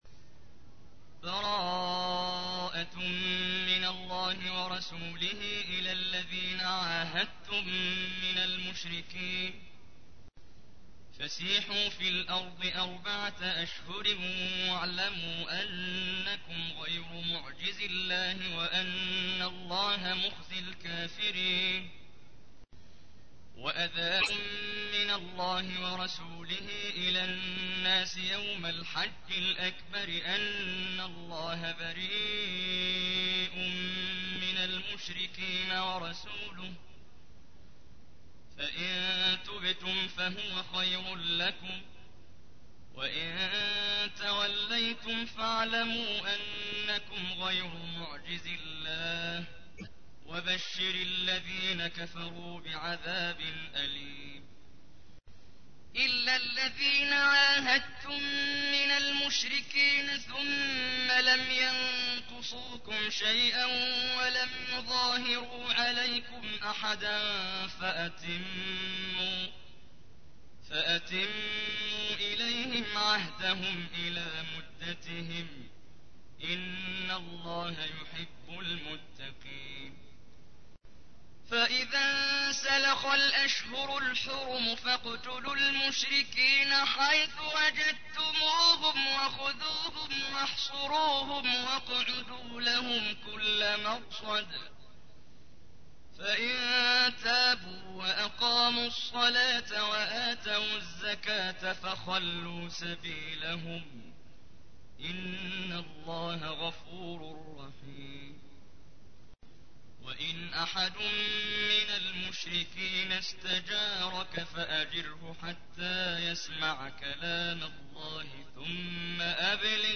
تحميل : 9. سورة التوبة / القارئ محمد جبريل / القرآن الكريم / موقع يا حسين